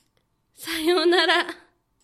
ボイス
ダウンロード 中性_「さようなら(笑)」
リアクション中音挨拶